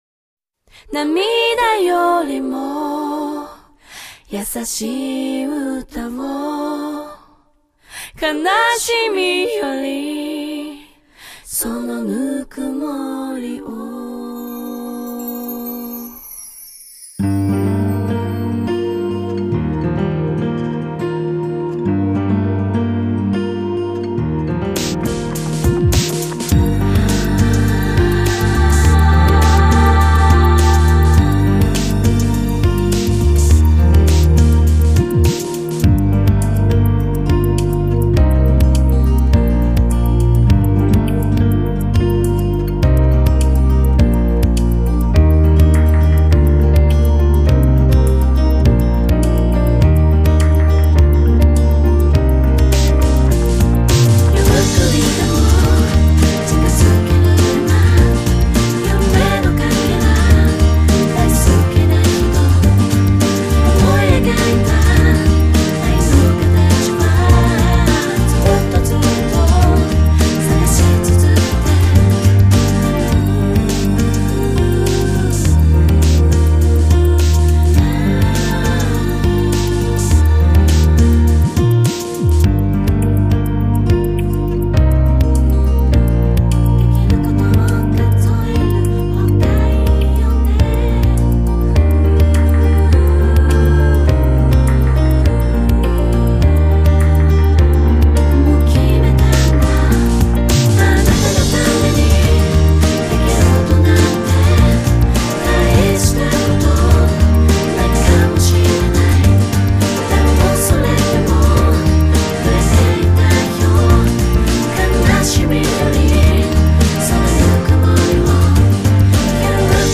Karaoké